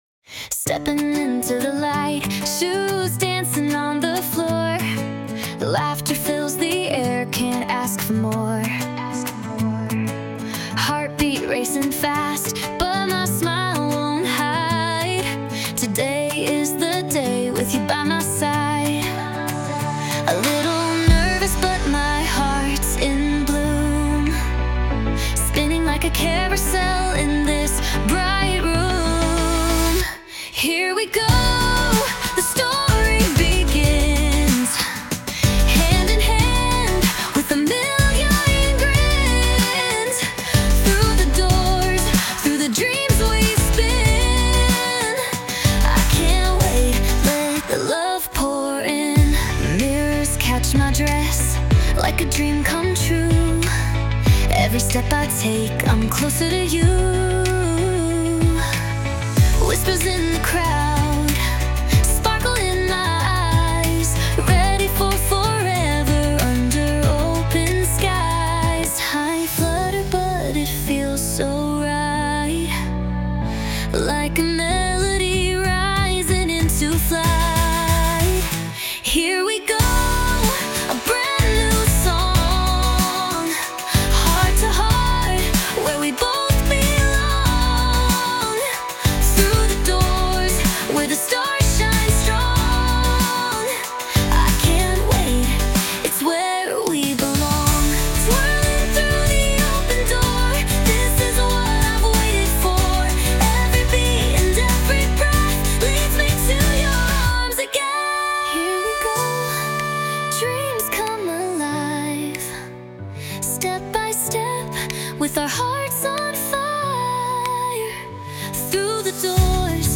洋楽女性ボーカル著作権フリーBGM ボーカル
著作権フリーオリジナルBGMです。
女性ボーカル（洋楽・英語）曲です。
そんな花嫁のリアルな心の揺れを、明るく前向きなメロディに乗せた、アップテンポな1曲です。